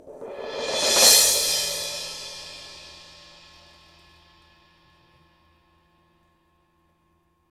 Index of /90_sSampleCDs/Roland LCDP03 Orchestral Perc/CYM_Cymbal FX/CYM_Mallet Rolls